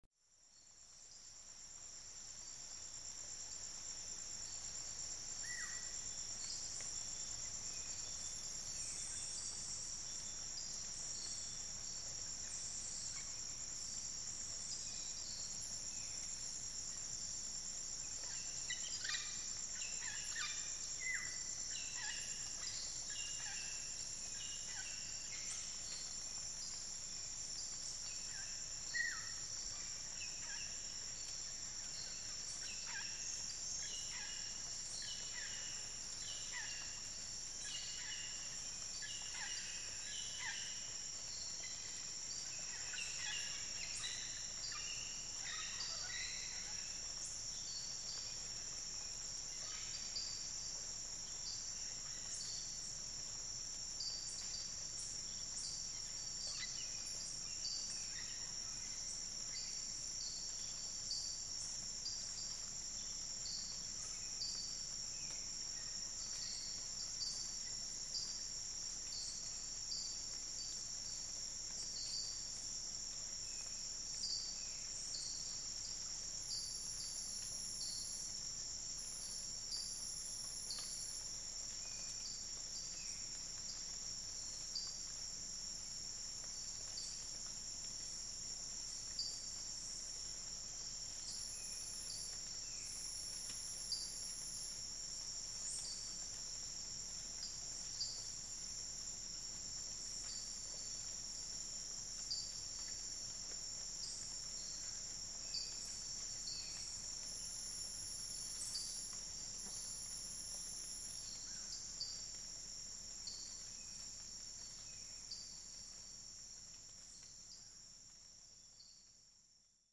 South American jungle, evening sounds, light rain: